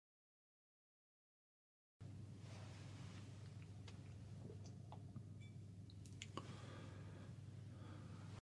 • MOUTH e SMACK ( Rumore generato dal movimento della bocca e delle labbra ), ( a. 22 ).
A.22 Mouth
mouth.mp3